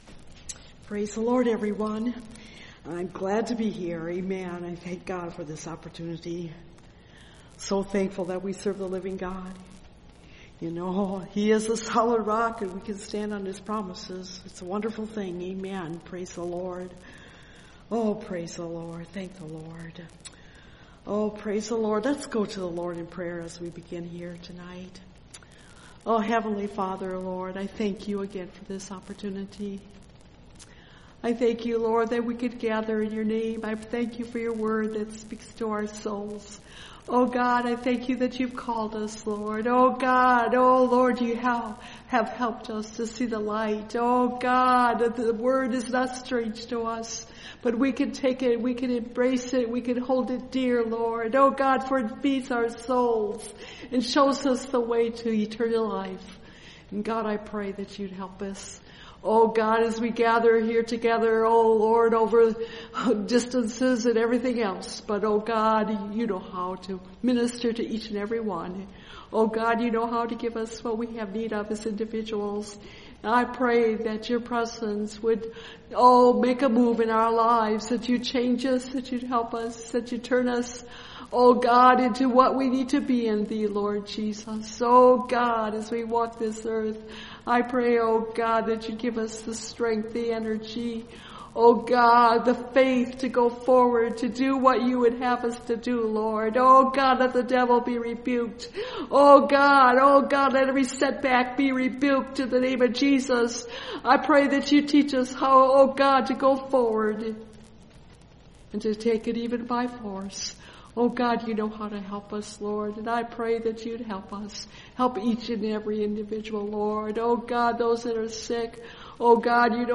By The Many Or The Few (Message Audio) – Last Trumpet Ministries – Truth Tabernacle – Sermon Library